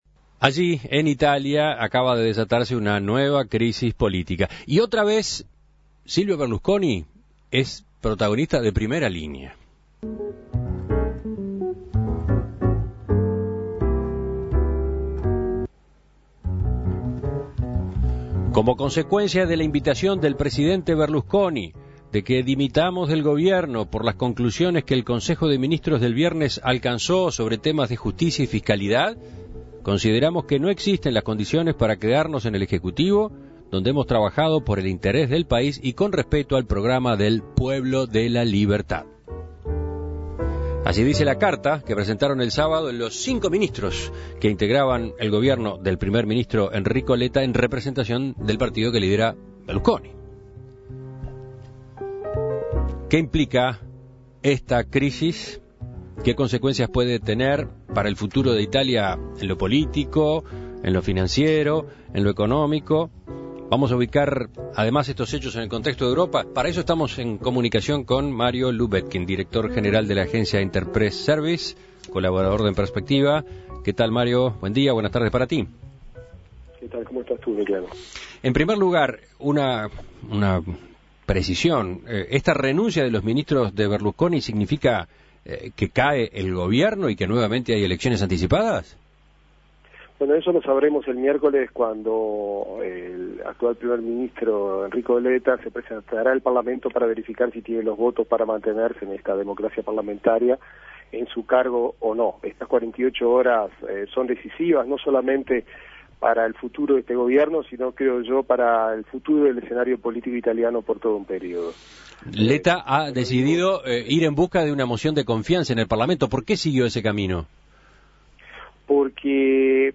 Contacto con Mario Lubetkin, colaborador de En Perspectiva en Italia.